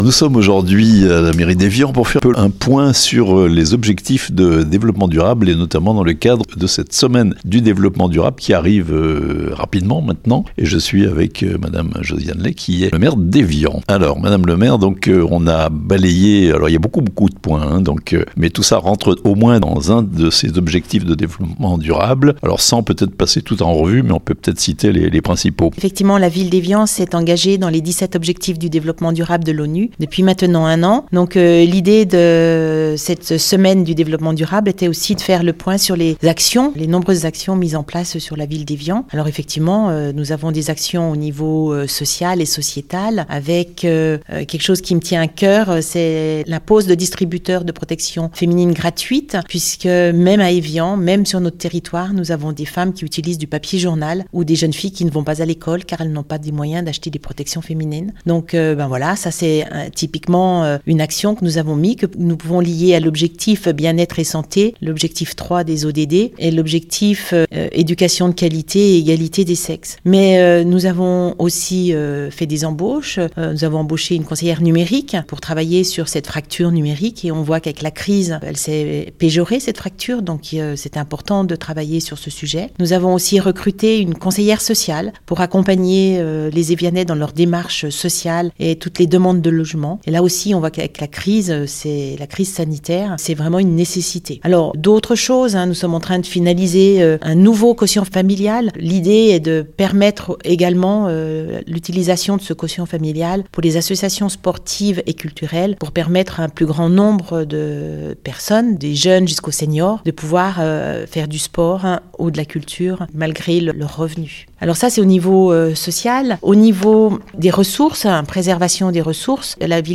La semaine du développement durable vue d'Evian (interviews)